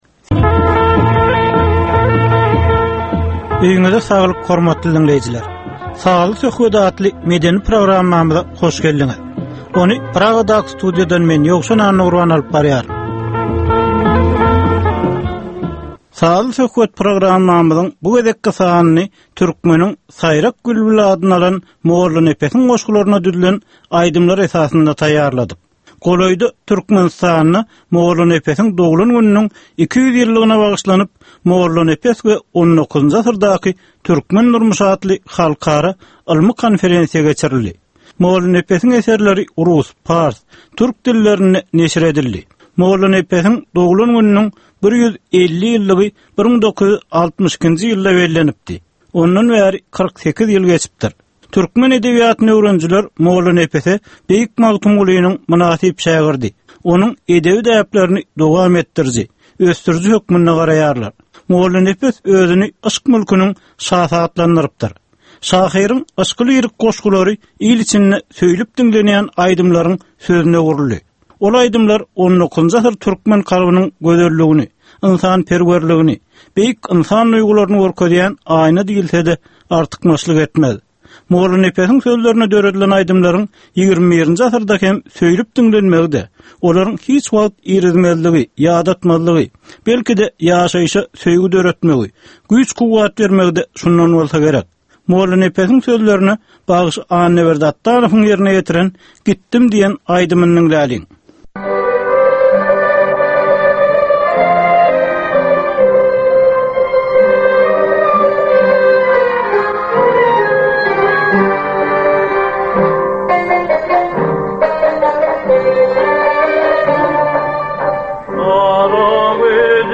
Türkmenistanyň käbir aktual meseleleri barada sazly-informasion programma.